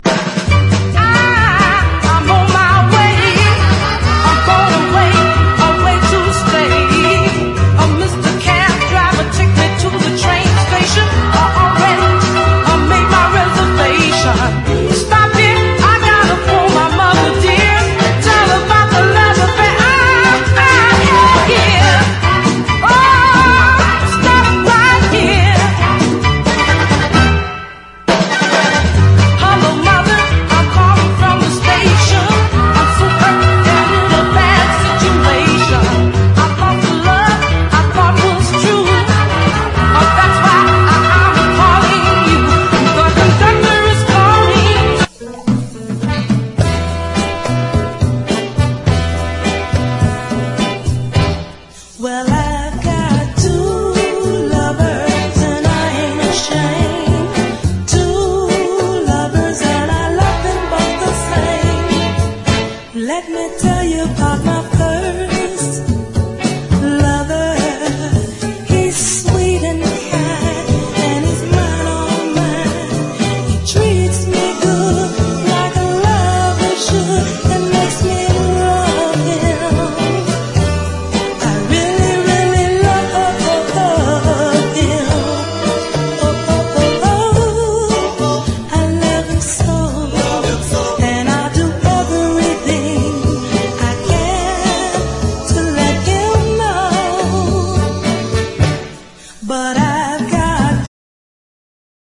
RHYTHM & BLUES
NEW ORLEANS FUNK
イントロにドラム・ブレイク入り